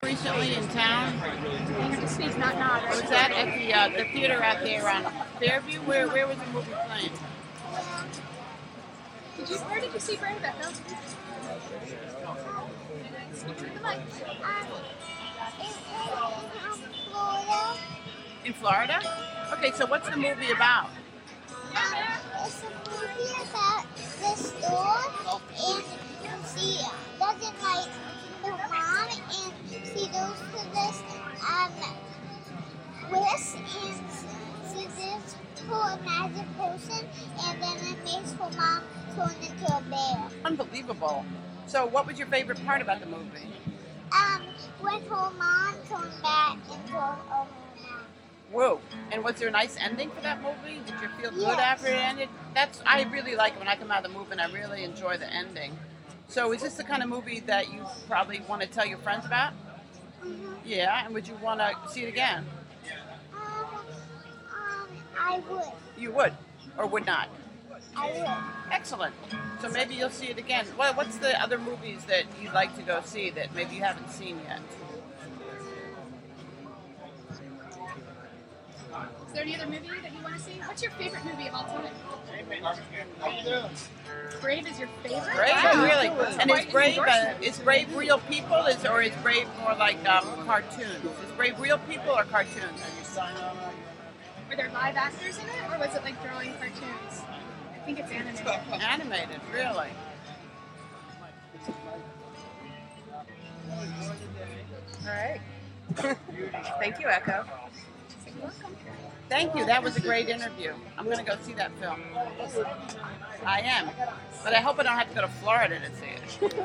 WGXC will broadcast live at the Athens Street Fest...
Interview